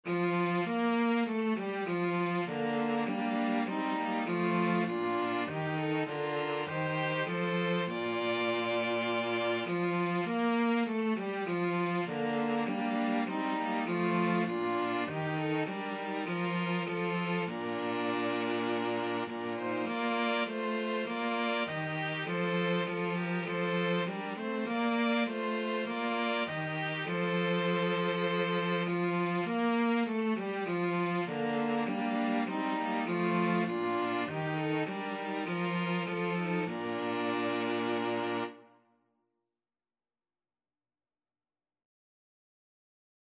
Free Sheet music for String trio
ViolinViolaCello
Bb major (Sounding Pitch) (View more Bb major Music for String trio )
Classical (View more Classical String trio Music)